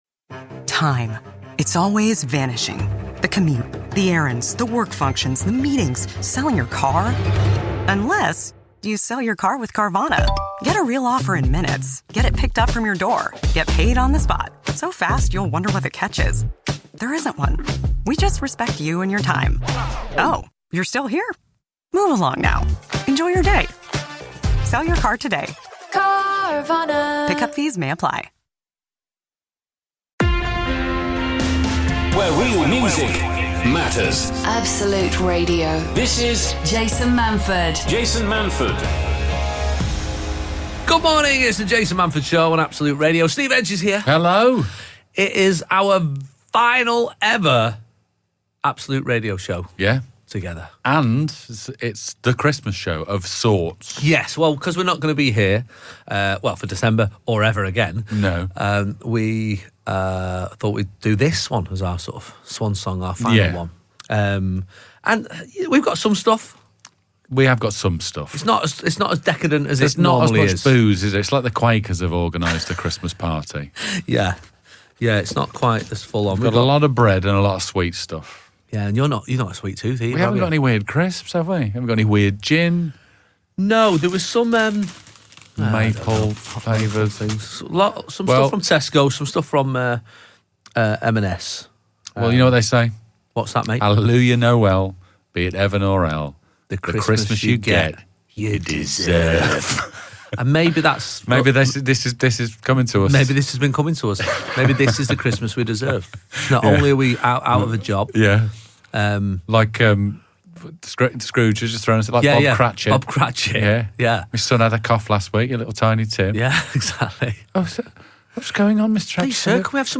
Jason Manford, one of the UK's most loved comedians, is on Absolute Radio every Sunday from 8am. If that sounds a bit early on a Sunday for you, then catch the best bits of Jason's show on this handy podcast..
… continue reading 458 episodes # Clean Comedy # Entertainment # Comedian Chat # Absolute Radio # Jason Manford # Funny # UK # Bauer Media # Comedy